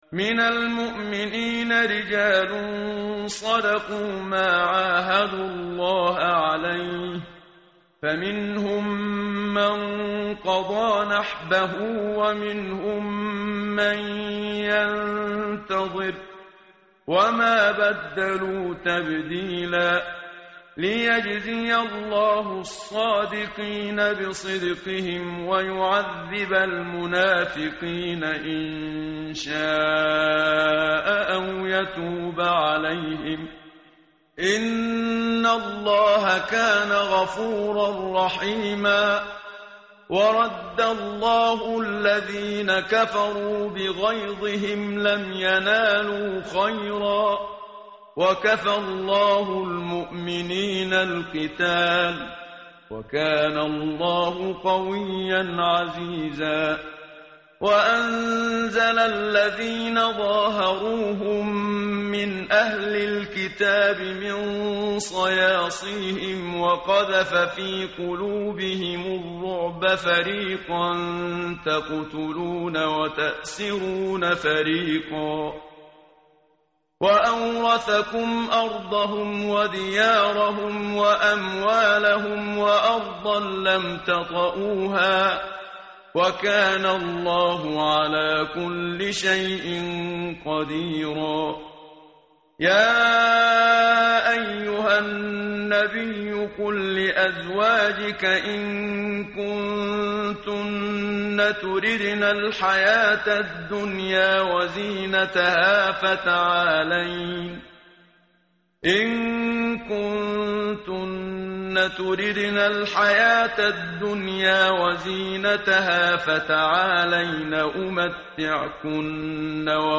tartil_menshavi_page_421.mp3